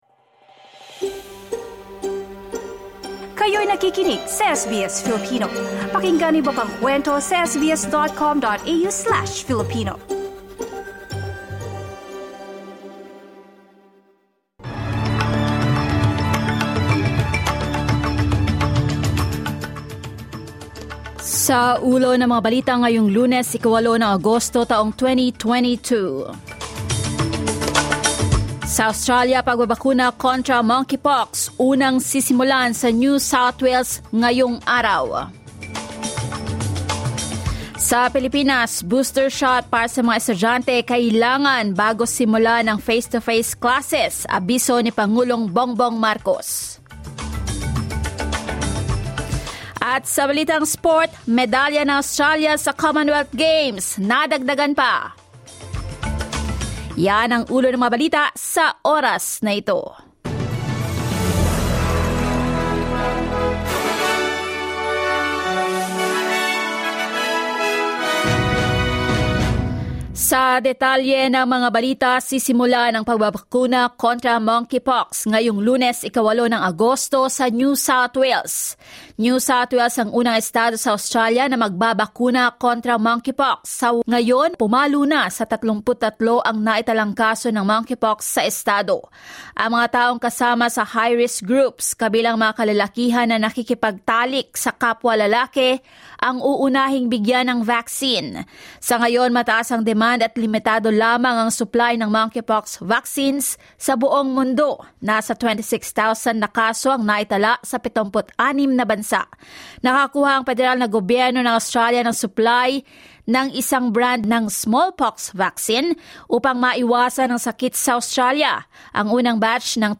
SBS News in Filipino, Monday 8 August